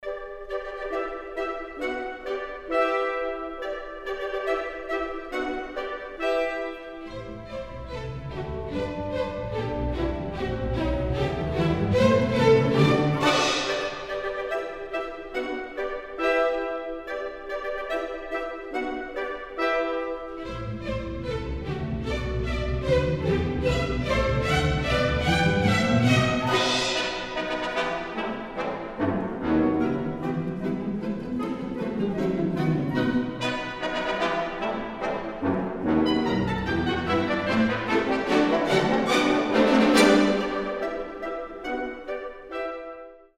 • Качество: 320, Stereo
без слов
оркестр